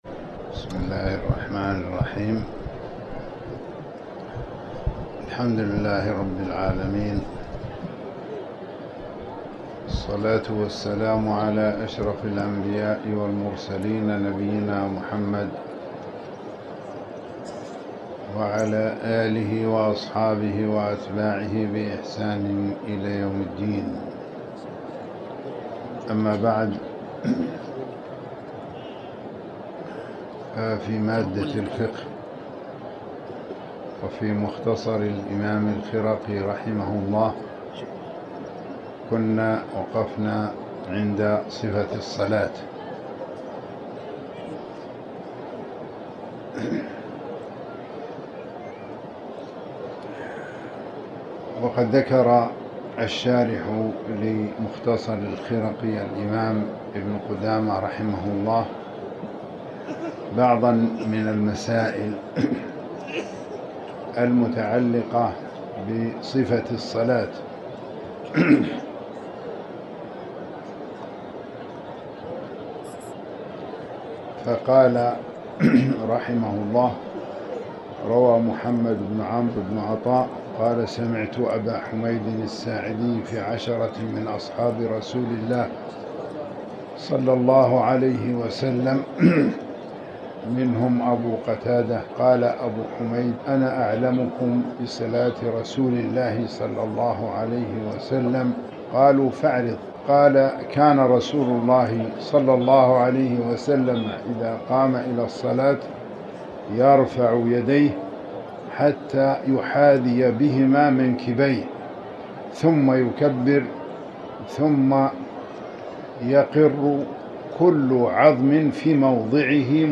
تاريخ النشر ٨ جمادى الآخرة ١٤٤٠ هـ المكان: المسجد الحرام الشيخ